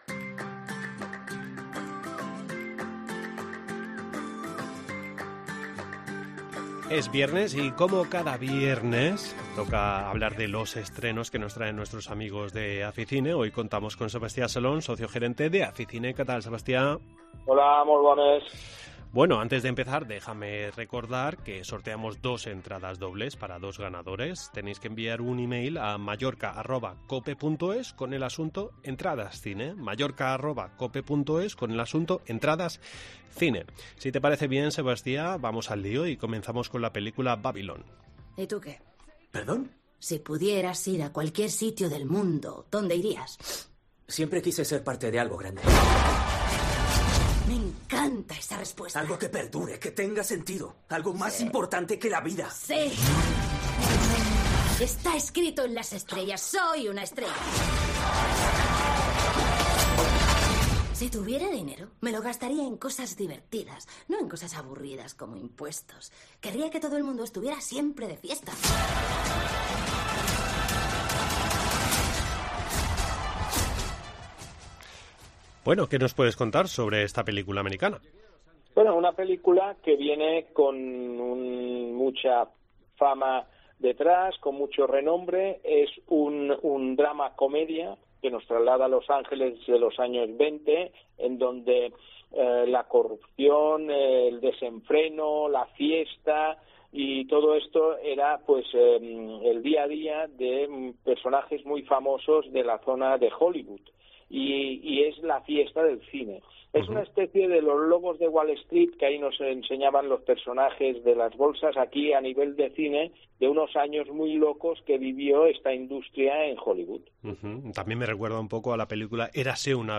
Entrevista en La Mañana en COPE Más Mallorca, viernes 20 de enero de 2023.